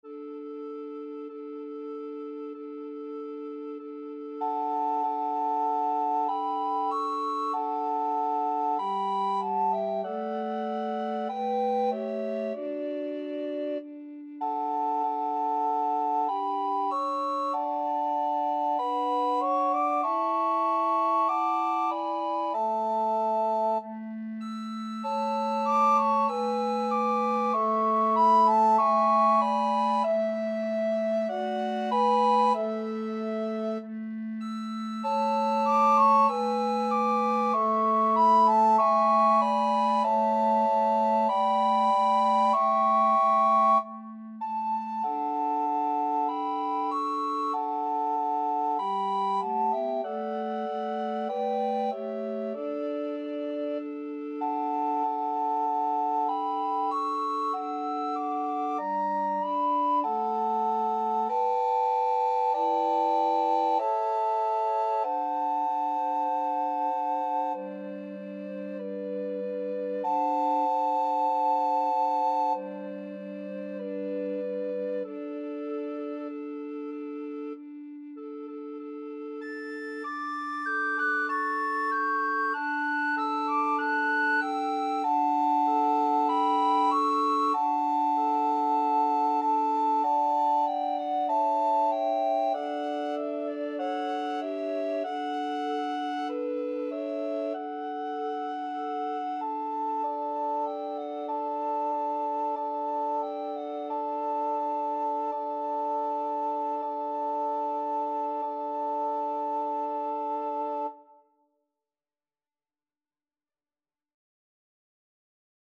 Soprano RecorderAlto RecorderTenor RecorderBass Recorder
2/4 (View more 2/4 Music)
Allegretto =96
Recorder Quartet  (View more Easy Recorder Quartet Music)
Classical (View more Classical Recorder Quartet Music)